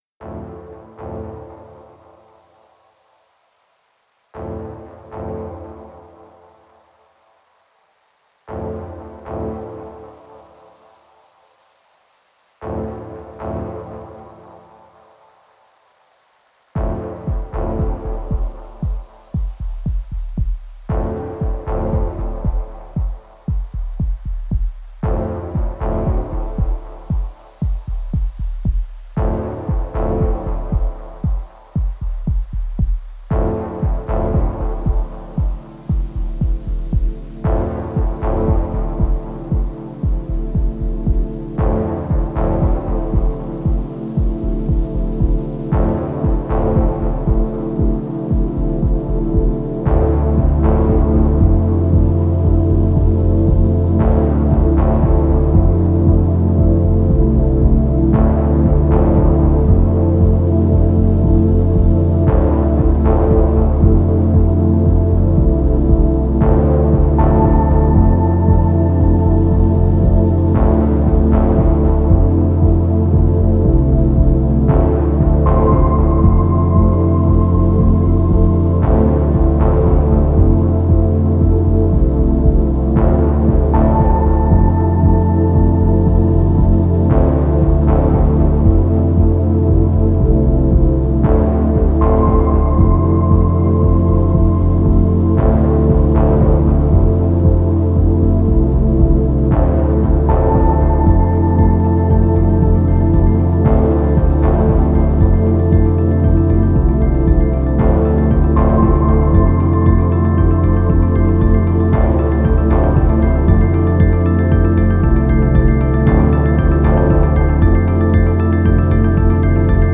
Genre: Dub Techno/Ambient/Deep House.